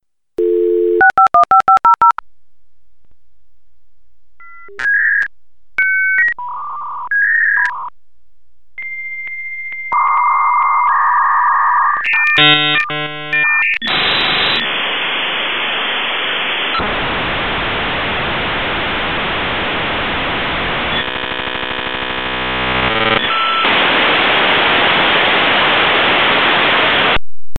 I have spent about six to seven years listening to those irritating bing-bongs.
dialup.mp3